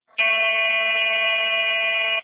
A-tune
The A- tune is a standard for instruments and orchestres in the most of the world, the chamber tune of 440Hz.
snaar5A.amr